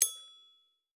Quiet Bell Notification.wav